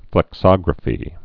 (flĕk-sŏgrə-fē)